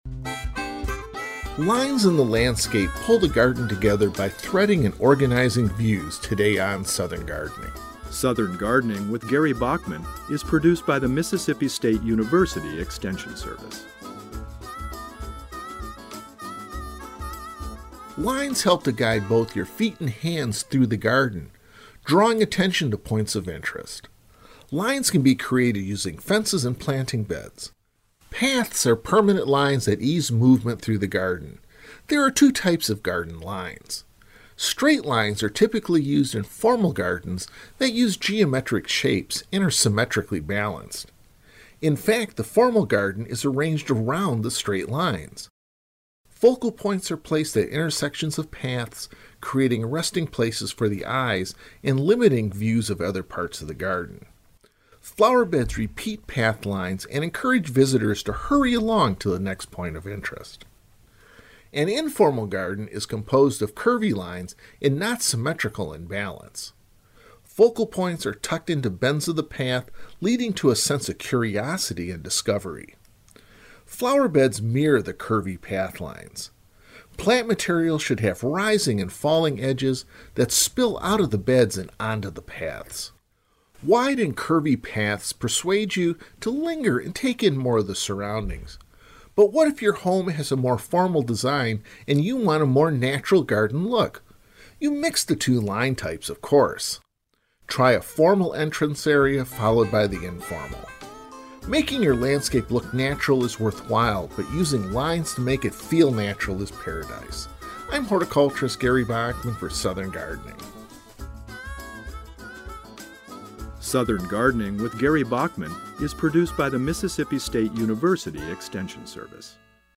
Host